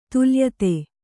♪ taleyettu